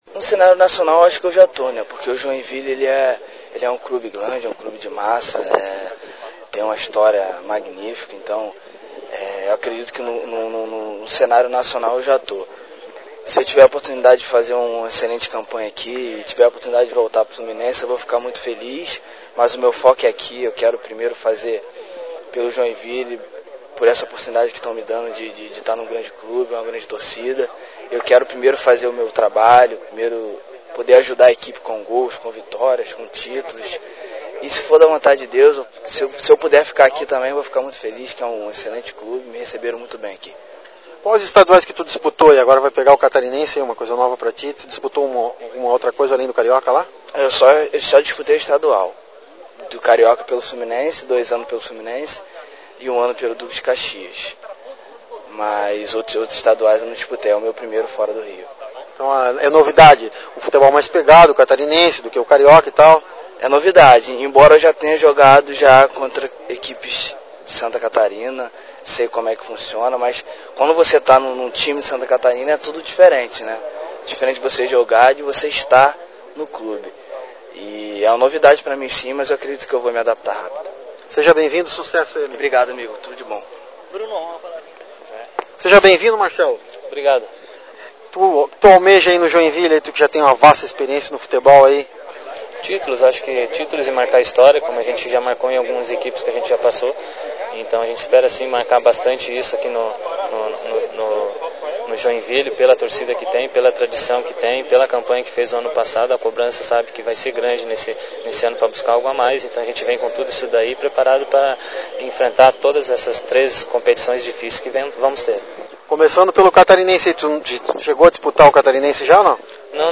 Áudio: Entrevista